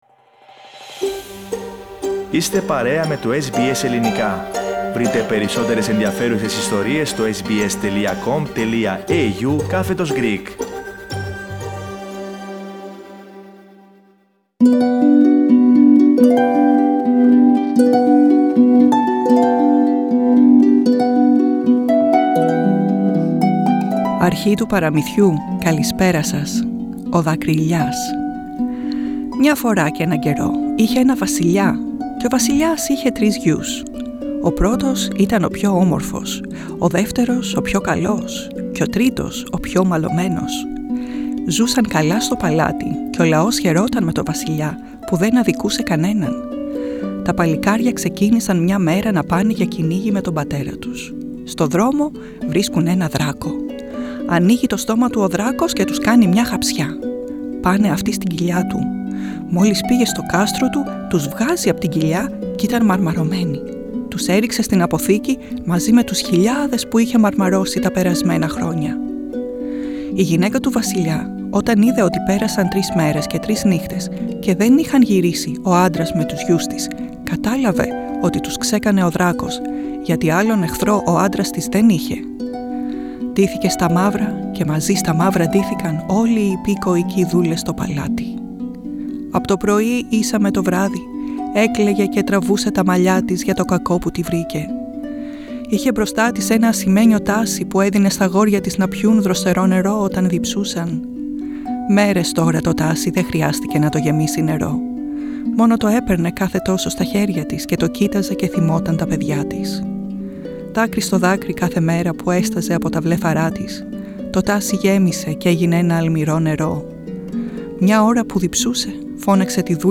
Ακούστε το παραδοσιακό παραμύθι Ο Δακρυηλιάς.